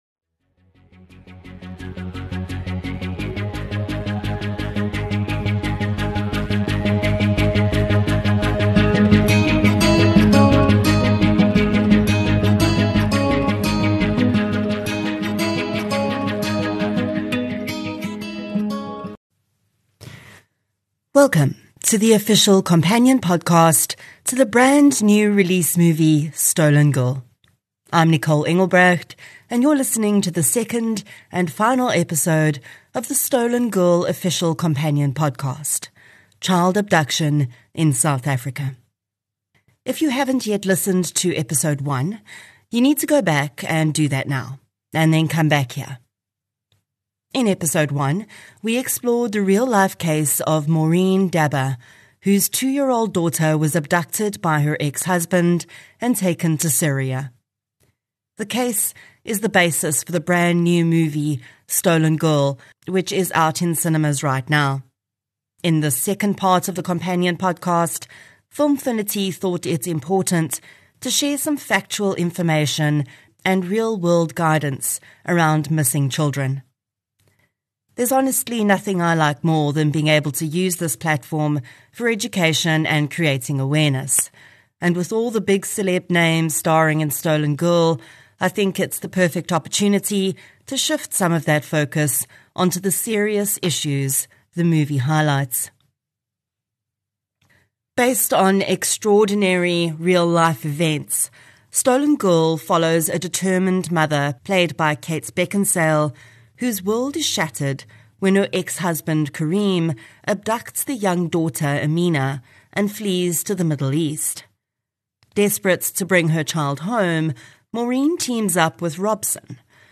This is the true story of a group of criminals who terrorised South Africa in 1913 and 1914 and the hidden legacy they left behind. Disclaimer voiceover
Production of intro and outro music